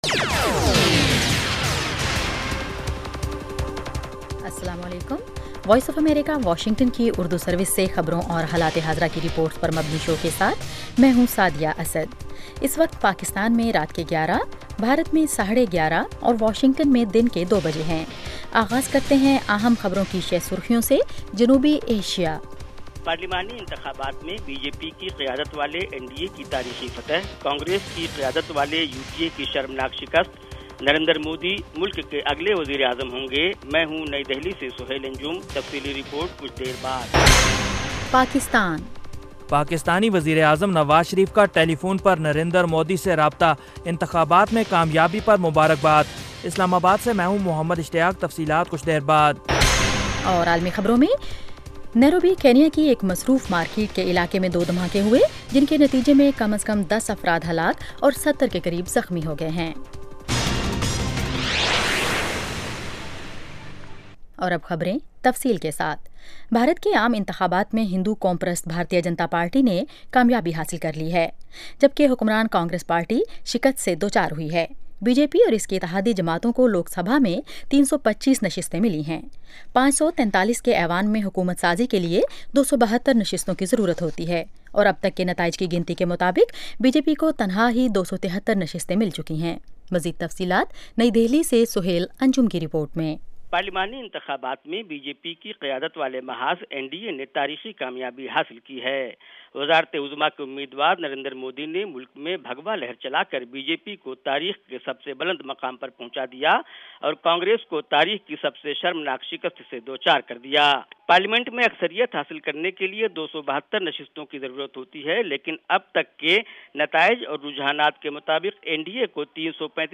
اس ایک گھنٹے کے پروگرام میں دن بھر کی اہم خبریں اور پاکستان اور بھارت سے ہمارے نمائندوں کی رپورٹیں پیش کی جاتی ہیں۔ اس کے علاوہ انٹرویو، صحت، ادب و فن، کھیل، سائنس اور ٹیکنالوجی اور دوسرے موضوعات کا احاطہ کیا جاتا ہے۔